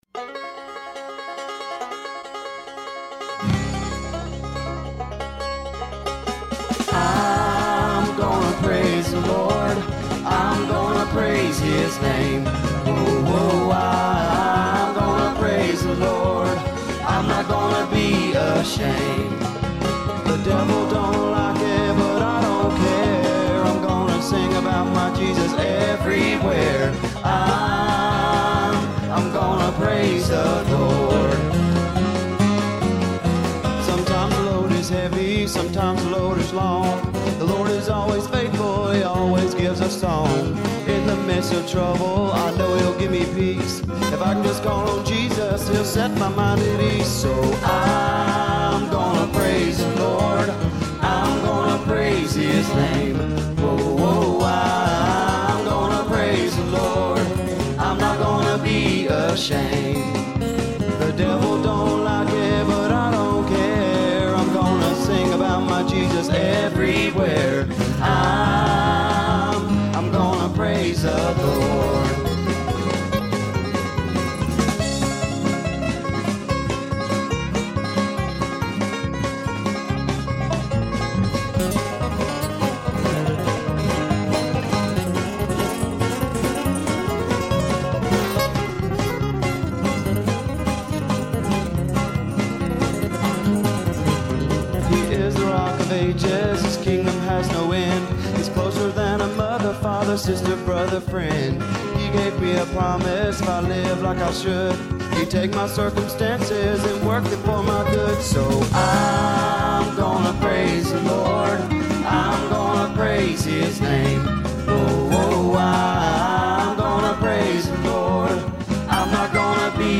Old gospel song. Country to tha core!